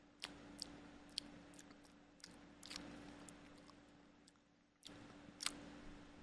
Tiếng Liếm môi, Liếm khi đánh Son môi
Thể loại: Tiếng động
Description: Âm thanh liếm môi, còn gọi liếm son, mút môi, chạm lưỡi, mơn trớn môi hay chu môi, tiếng khi đánh son, trang điểm, làm đẹp ... trong dựng video, sound-effect làm phim...
tieng-liem-moi-liem-khi-danh-son-moi-www_tiengdong_com.mp3